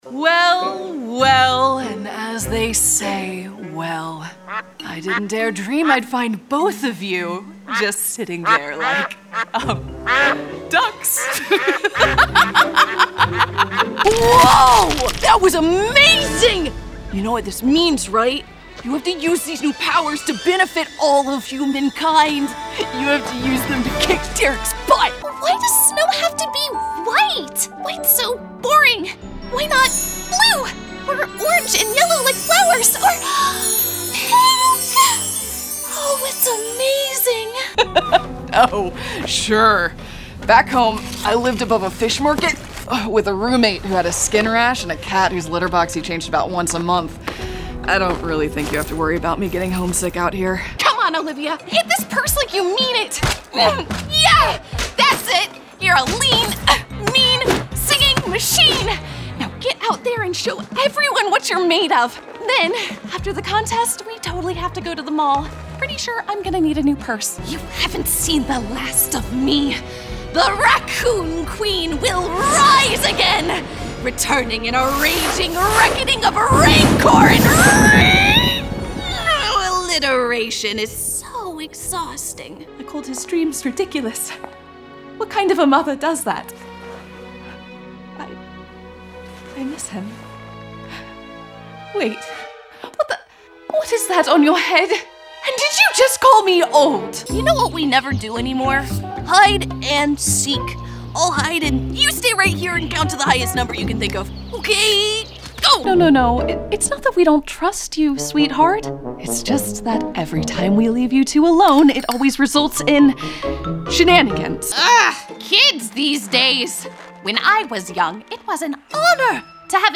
Teenager, Young Adult, Adult
Has Own Studio
I’m a highly adaptable actor, and I bring warmth, passion, and a touch of brightness to my performances.
ANIMATION 🎬